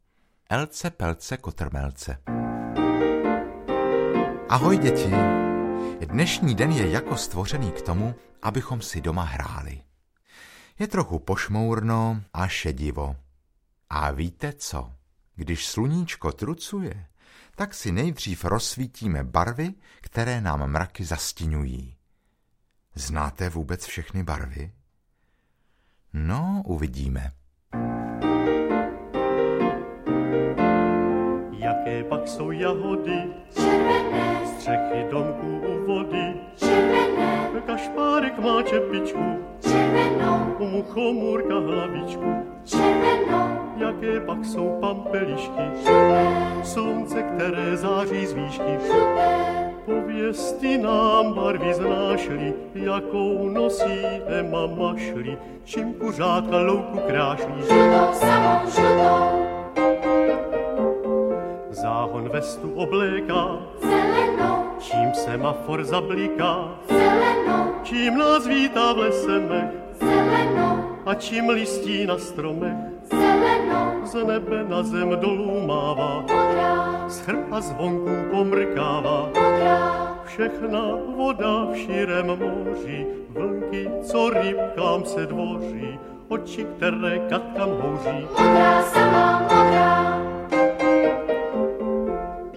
Veselé písničky s hravým cvičením pro děti malé i větší!